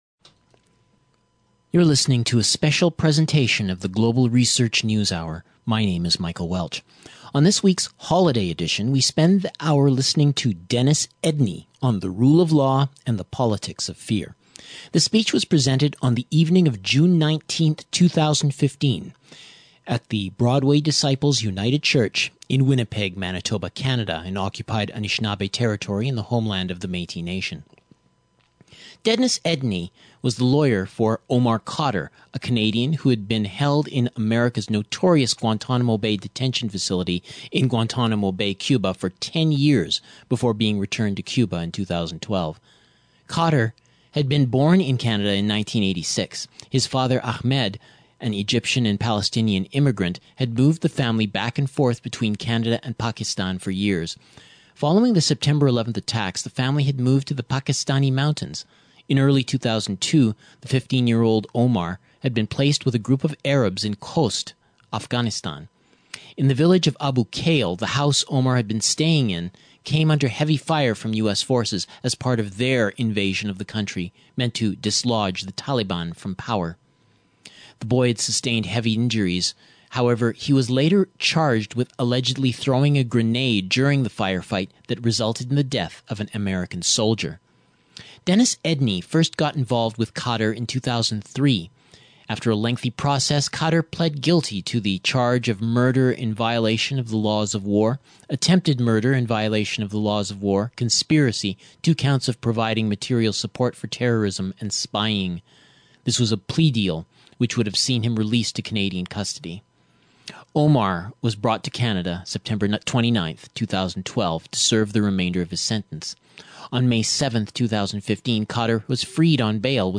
A 2015 speech